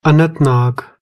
Anantnag (Urdu pronunciation: [ənənt̪nɑːɡ] ; Kashmiri pronunciation: [anant̪naːɡ]
Anantnag_IPA.ogg.mp3